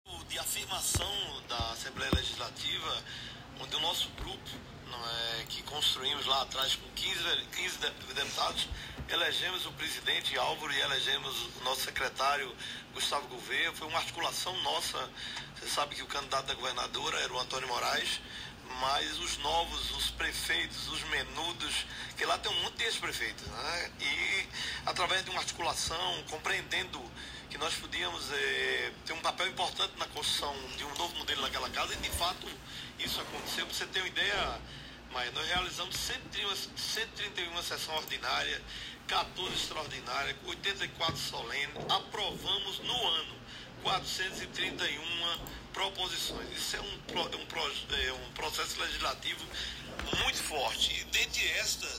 Trecho da entrevista do deputado